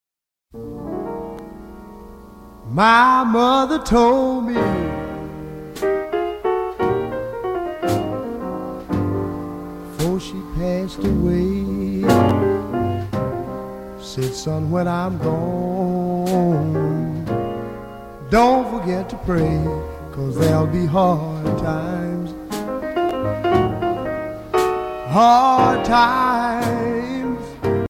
danse : slow